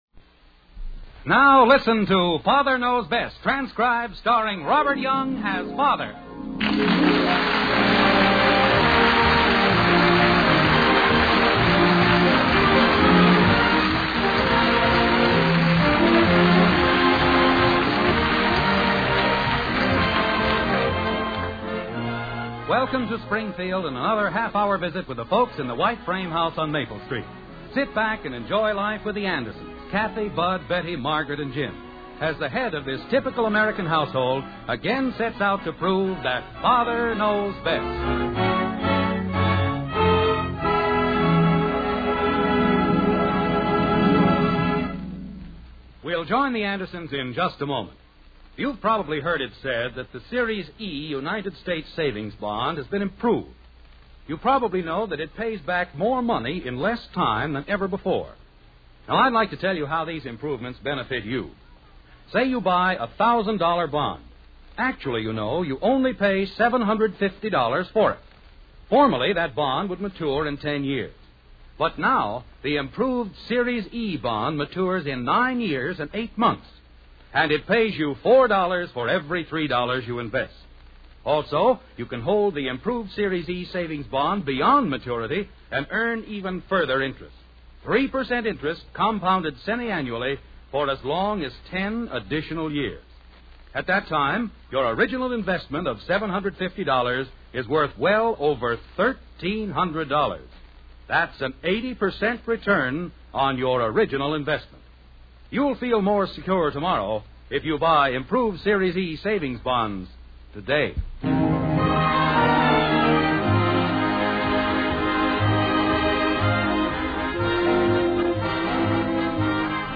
The Father Knows Best Radio Program